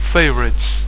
Plop, plop, plop,...